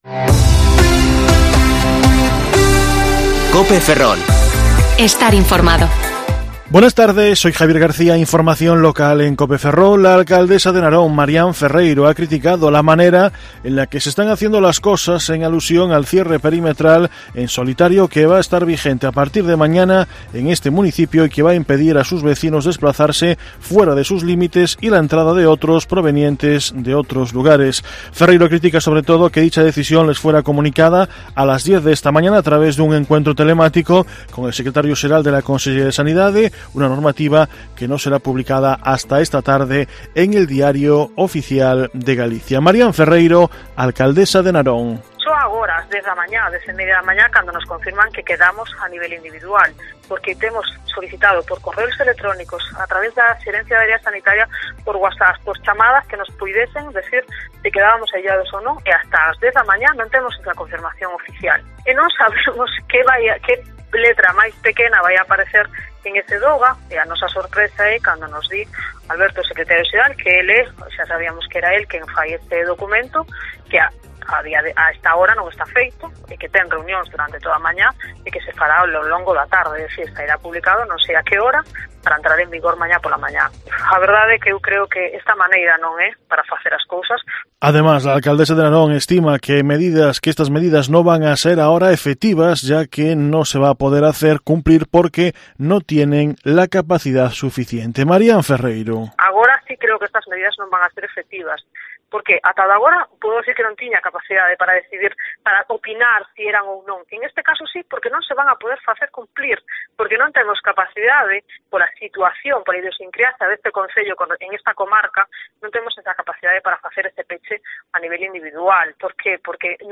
Informativo Mediodía COPE Ferrol 3/12/2020 (De14,20 a 14,30 horas)